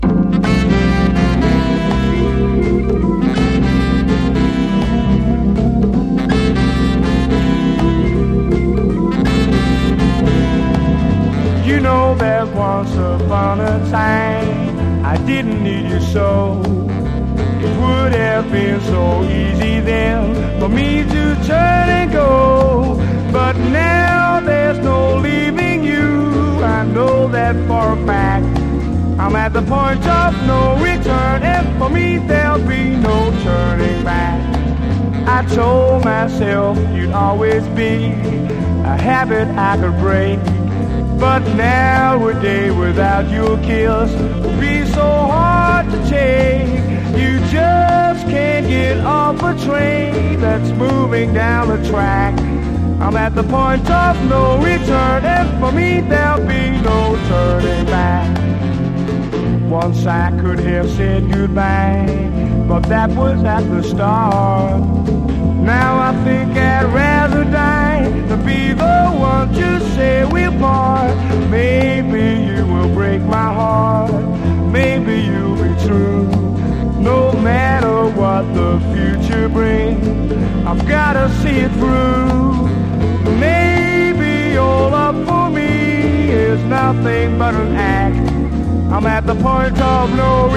モッド・クラシックなキラー・カヴァー多数の1964年録音！